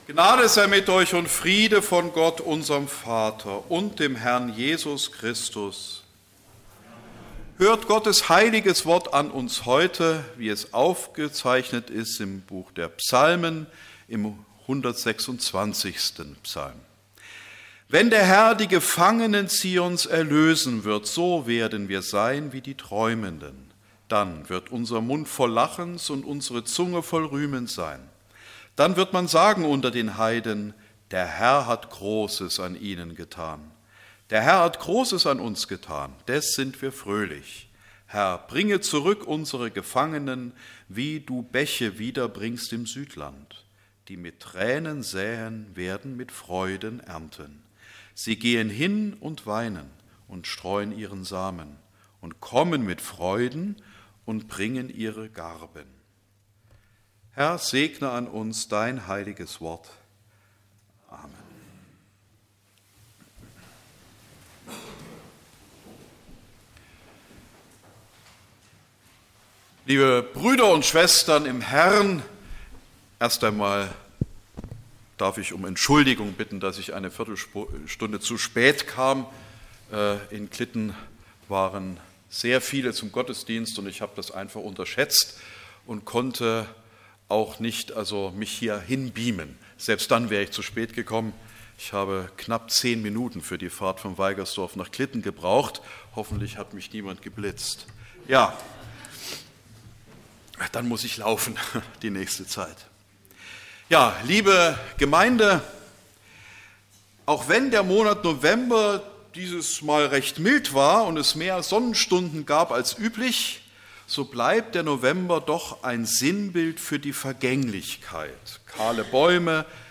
Predigten der SELK Weigersdorf